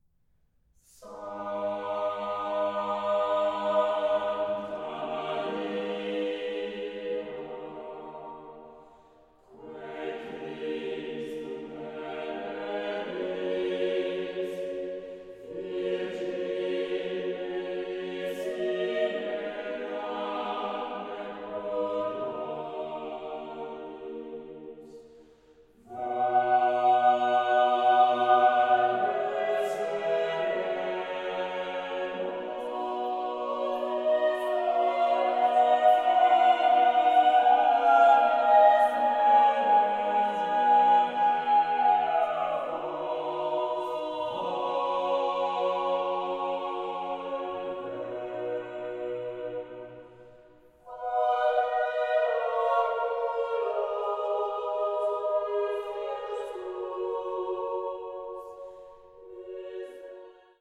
soprano
tenors
organ
sounding revelatory as choral works in sacred guise.